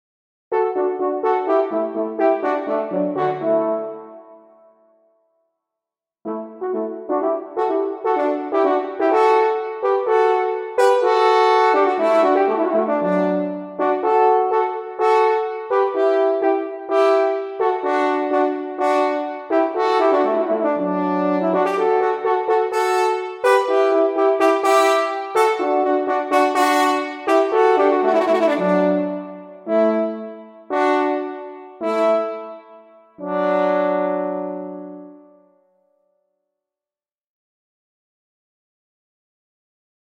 Horn Triple | VSL Forum
That's the Triple Horn sound of VSL (two voices):
BK_Triple_Horn.mp3